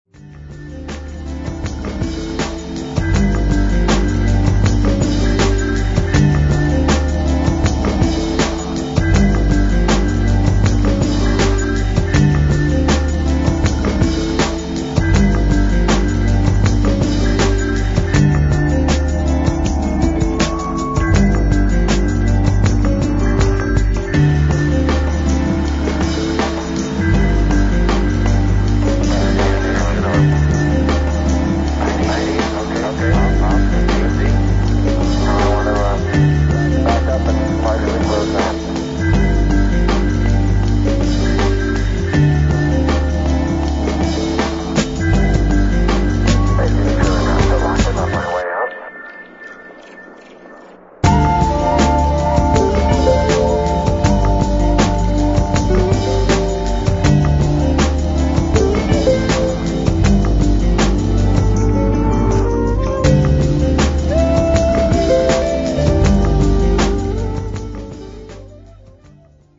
[Electro]